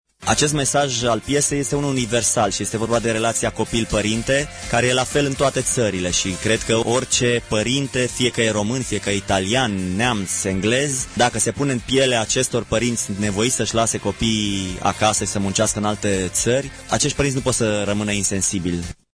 Piesa trupei Voltaj atrage atenţia asupra situaţiei copiilor lăsaţi în urmă de părinţii plecaţi la muncă în străinătate, a explicat la RRA solistul grupului, Călin Goia.